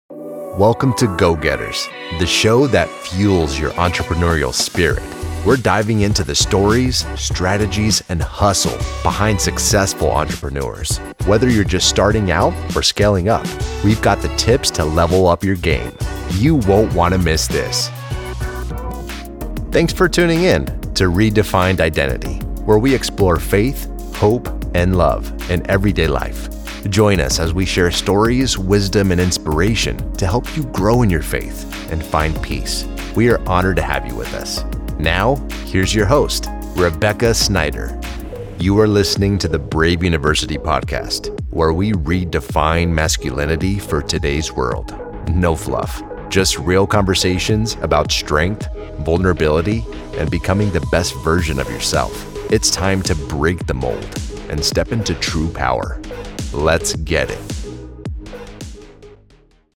Voice-Over Artist
Upbeat, Confident, Sincere
Podcast-Demo.mp3